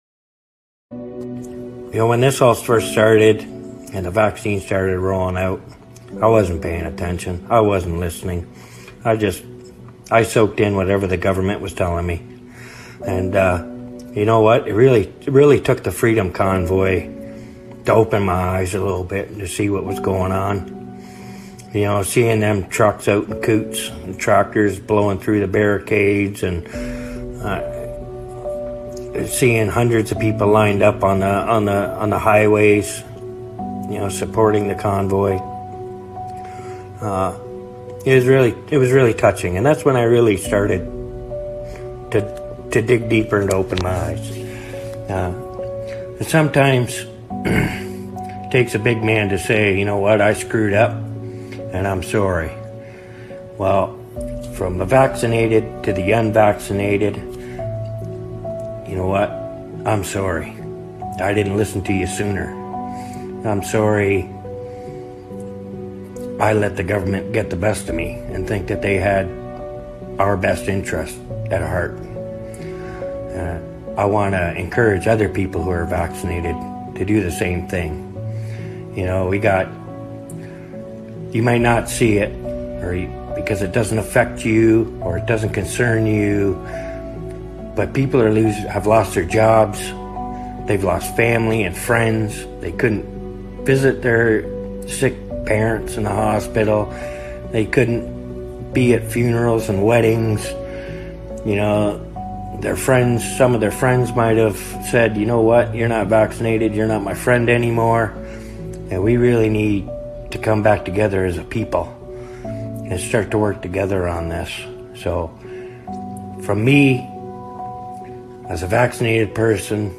הבחור מודה בטעות שעשה ןמבקש סליחה מכל הלב מהלא מחוסנים על שלא הקשיב להם בזמן.